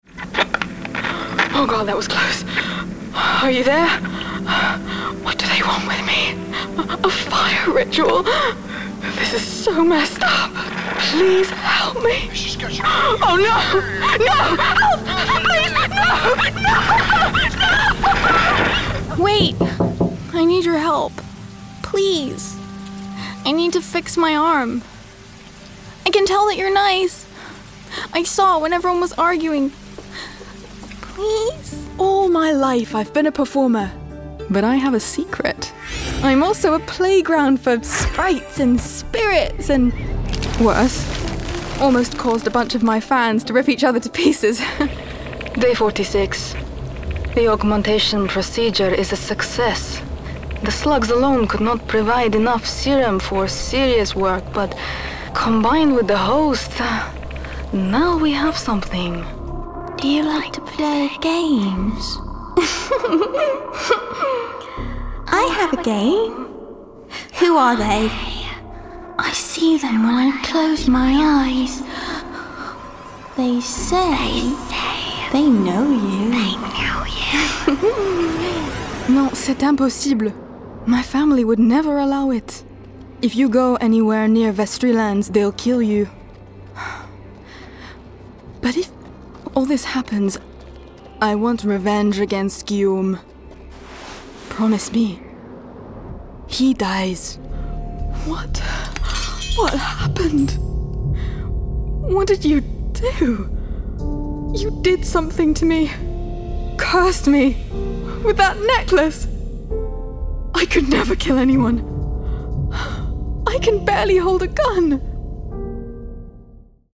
Gaming Reel
• Home Studio
Soft and melodic
A multi-national, her voice bends effortlessly from one accent to the next, all utterly believable and with a characterful bite.